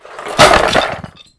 BoxFall.wav